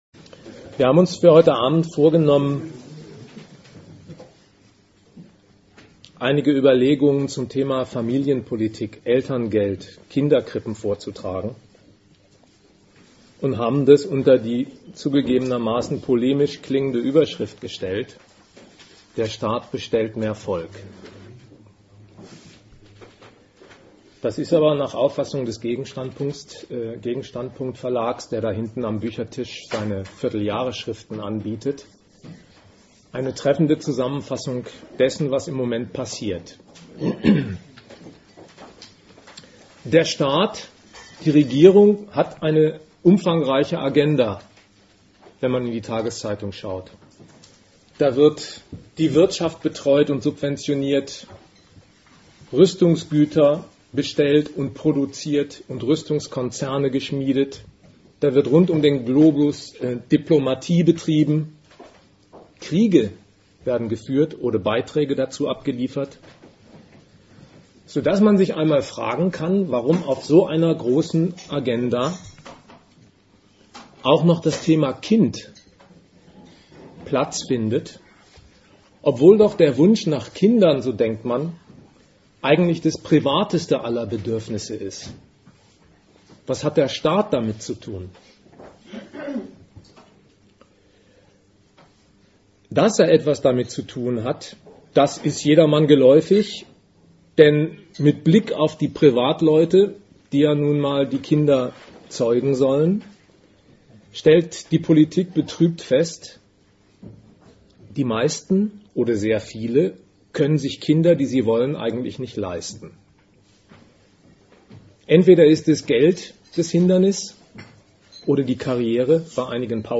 Teil 4. Diskussion Veranstalter: Forum Kritik Regensburg Weitere Publikationen zum Thema: Artikel zu Thema Staat und Familie finden sich im Archiv des GegenStandpunkt-Verlages.